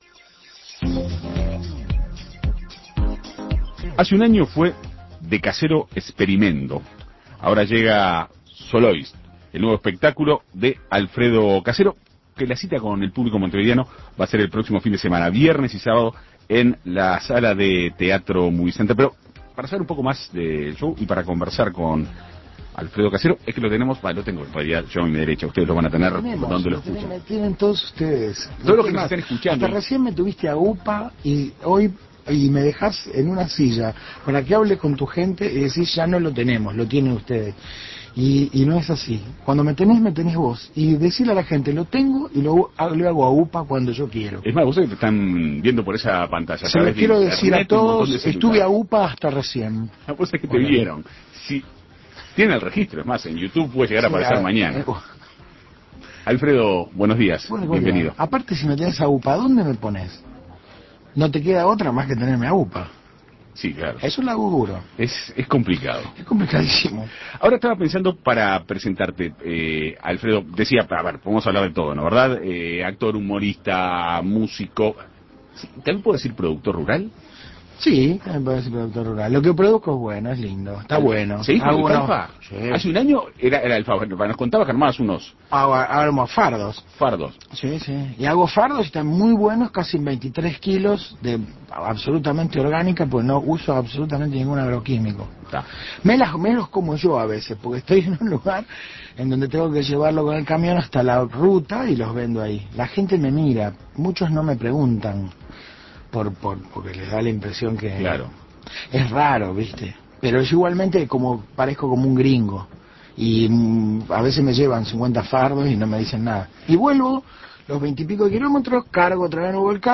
Entrevista con Alfredo Casero.